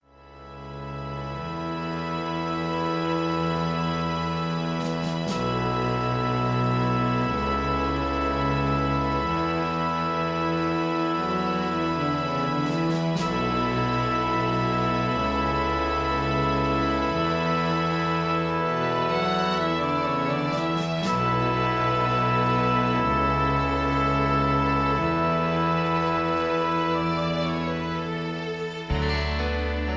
karaoke, studio tracks, sound tracks, backing tracks, rock